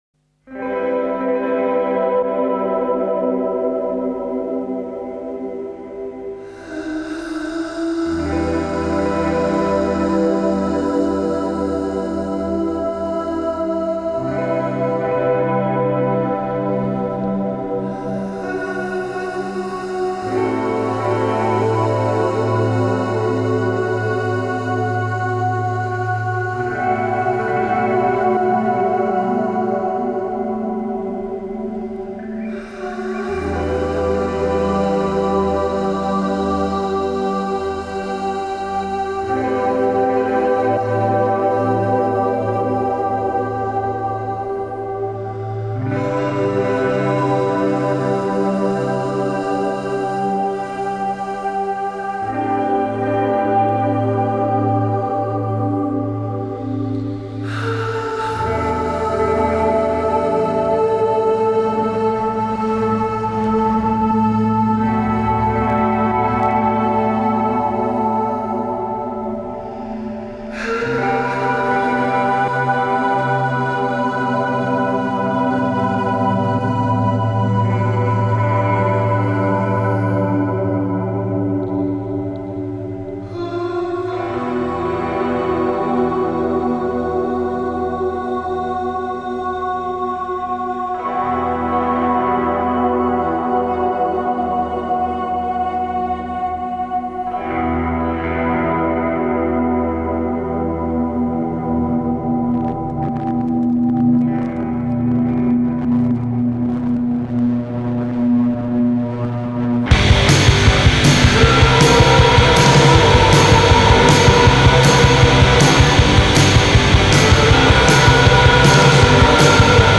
German mood rock will lead the Hamburg scene to fame
Vocals
Guitar
Bass
Drums